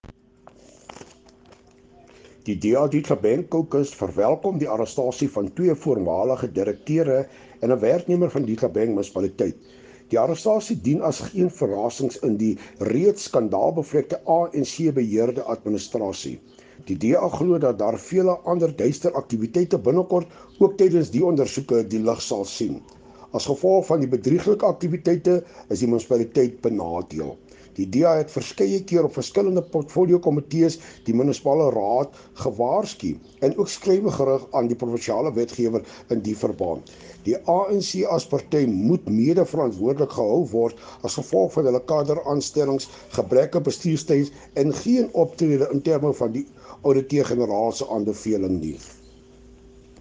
Afrikaans soundbites by Cllr Hilton Maasdorp.